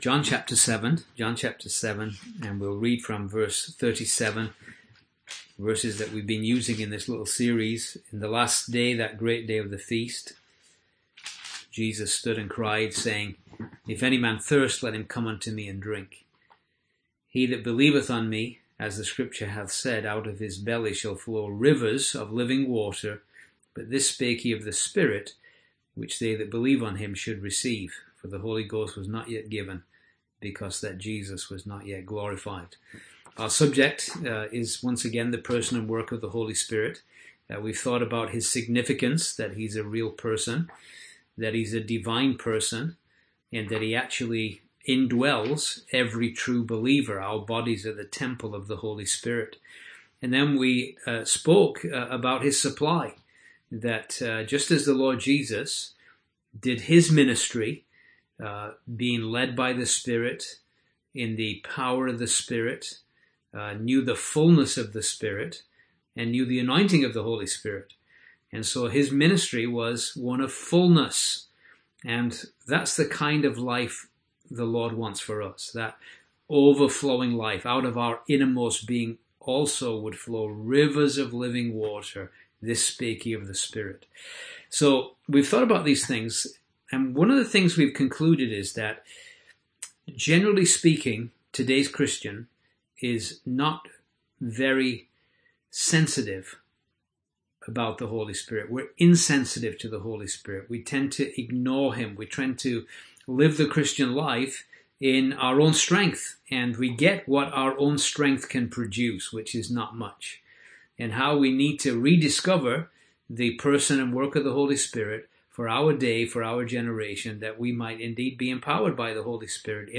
Service Type: Sunday AM Topics: Holy Spirit , Reconciliation , Relationships